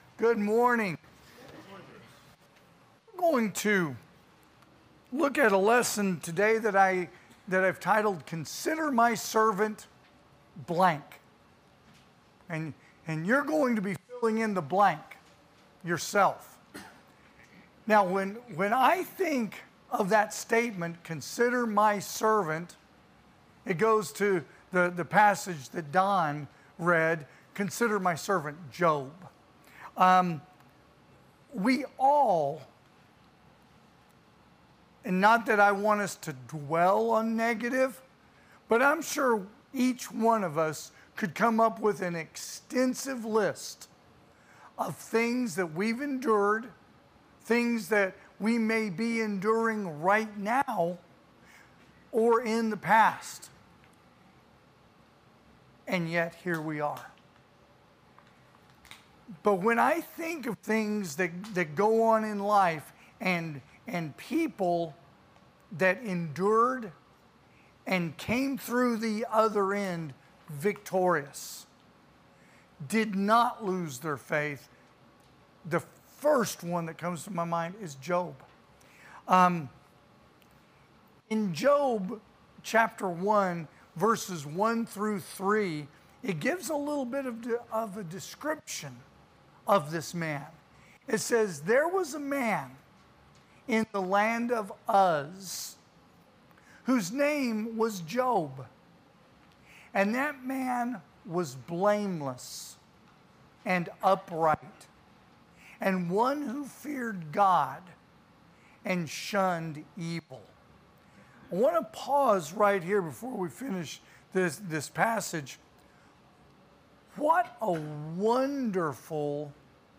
2025 (AM Worship) "Consider My Servant